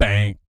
BS BANG 05.wav